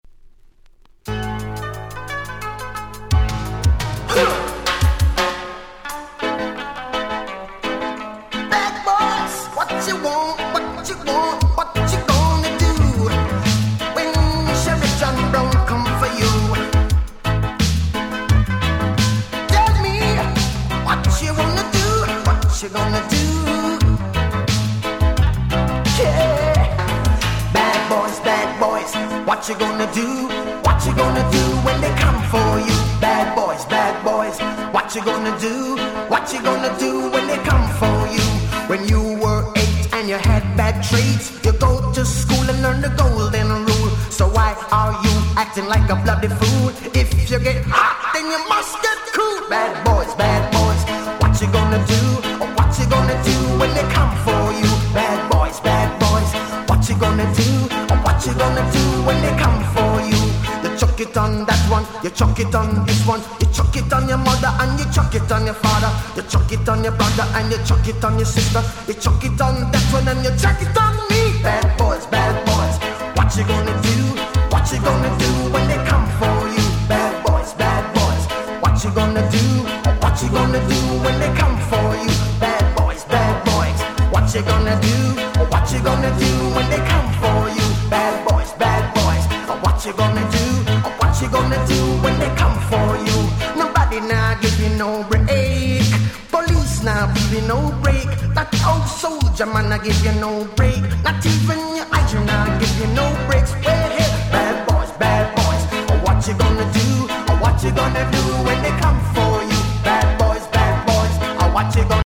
93' Smash Hit Reggae !!
上記の2曲と比べてしまうと若干地味ではございますが、こちらもCoolで非常に格好良い1曲です。